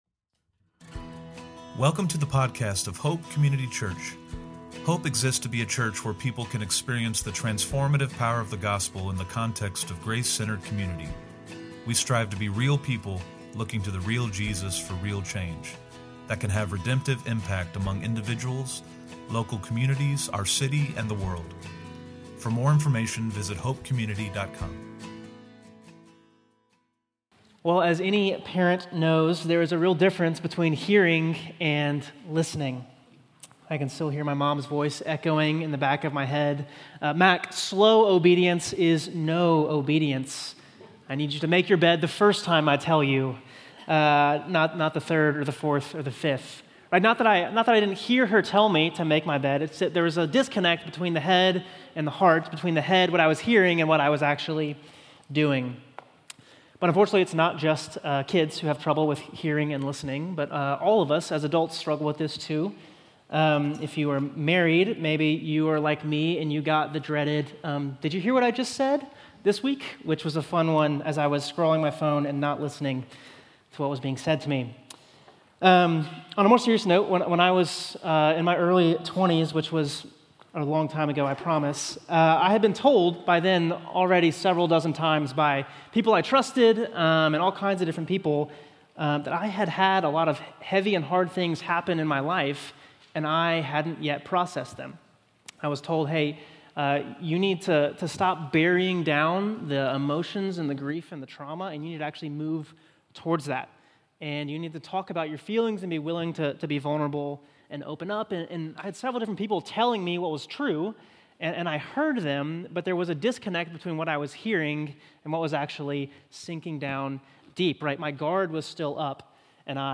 Sermons & Studies - Hope Community Church
From Location: "Olde Providence"